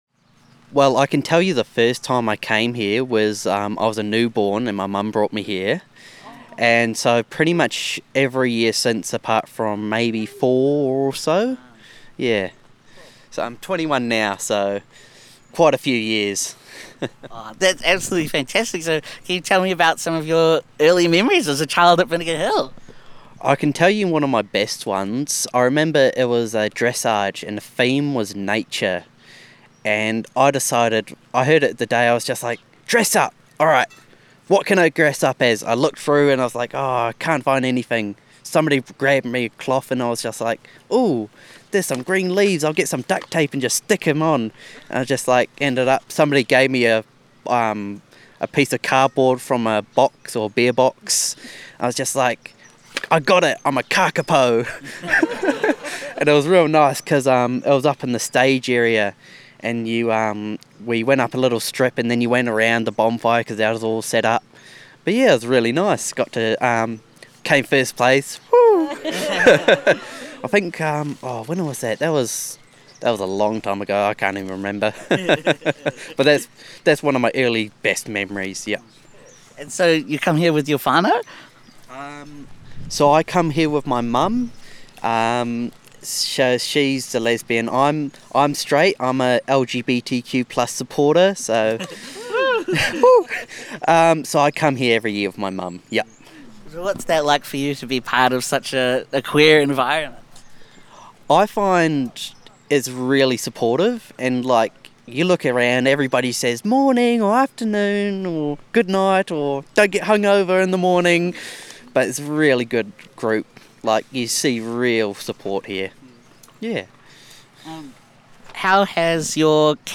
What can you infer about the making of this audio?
Location:Vinegar Hill / Putai Ngahere Domain, Manawatū-Whanganui